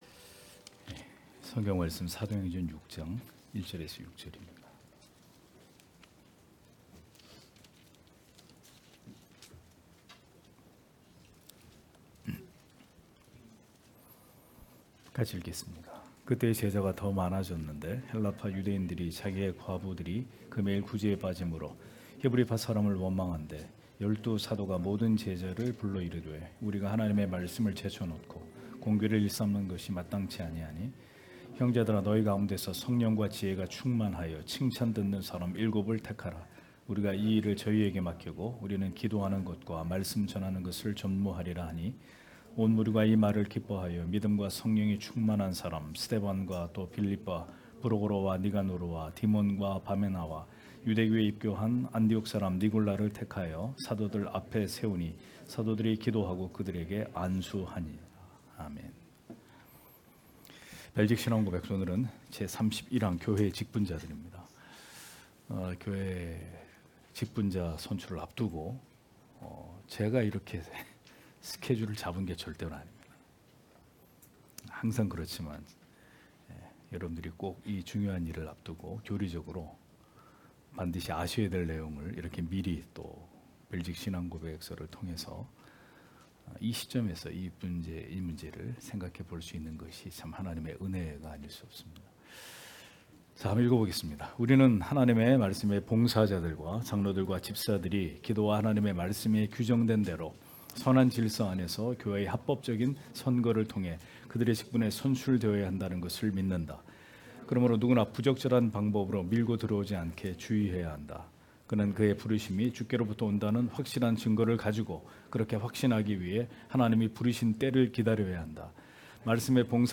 주일오후예배 - [벨직 신앙고백서 해설 36] 제31항 교회의 직분자들 (행 6장1-6절)